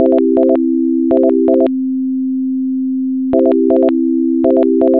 Now let’s test our function for adding in a Cantor progression of tones.
# Now let's try set of Cantor tones; the frequency to use at each level will be a frequency
# from the guitar C chord.